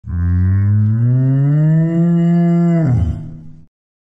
دانلود آهنگ مو مو گاو 3 از افکت صوتی انسان و موجودات زنده
دانلود صدای مو مو گاو 3 از ساعد نیوز با لینک مستقیم و کیفیت بالا
جلوه های صوتی